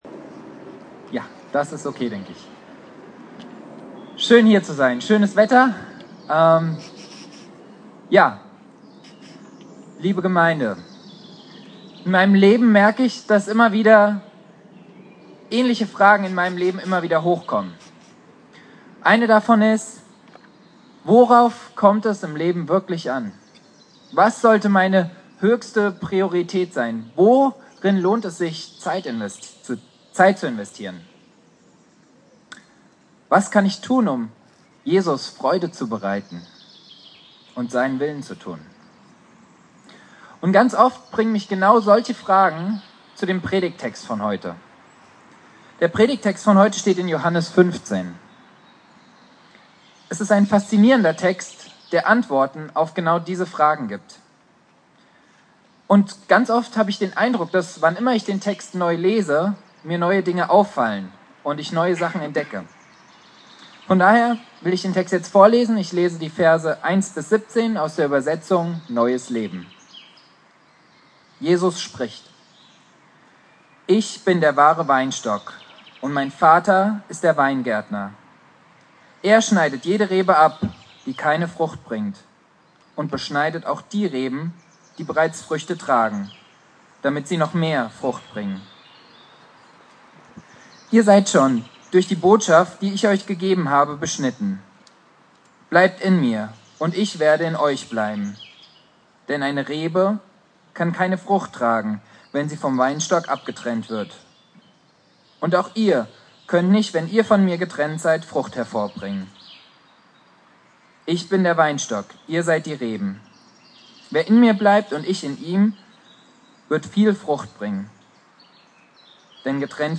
Predigt
Christi Himmelfahrt